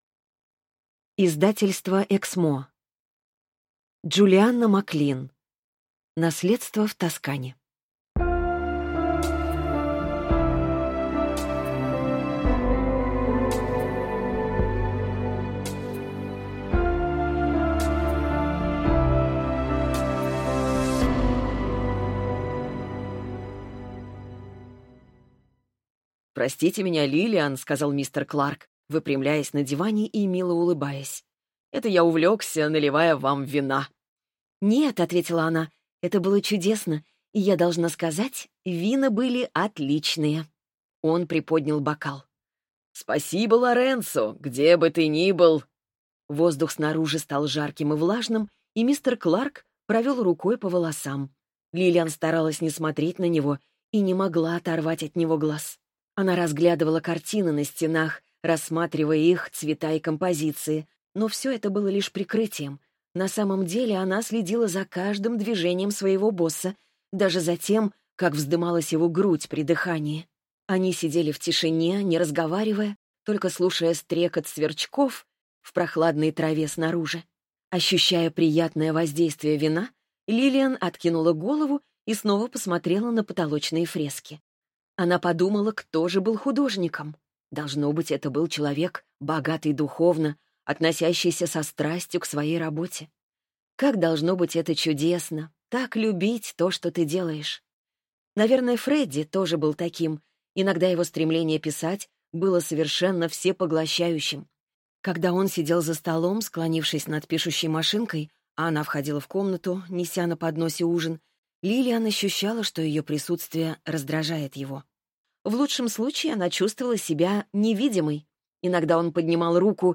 Аудиокнига Наследство в Тоскане | Библиотека аудиокниг